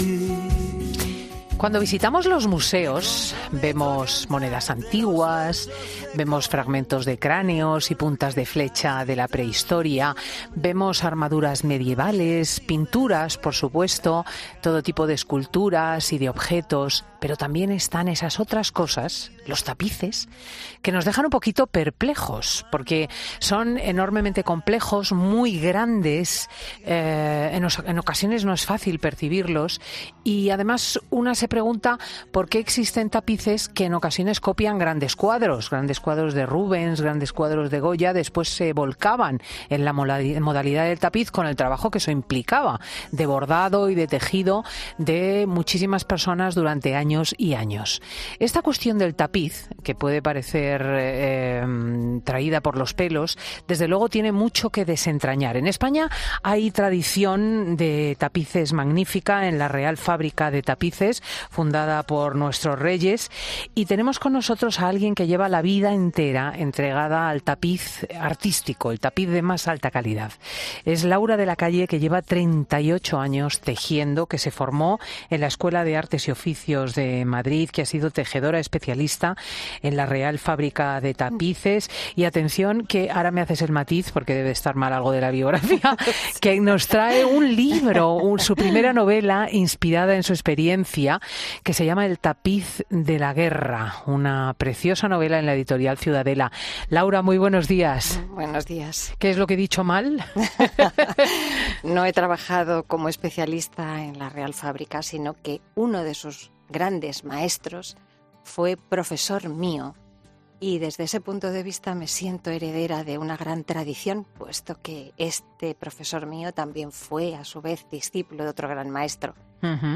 En España hay tradición de tapices magnífica en la Real Fábrica de Tapices , fundada por nuestros reyes, y en Fin de Semana hemos hablado con alguien que lleva la vida entera entregada al tapiz artístico, el de más alta calidad.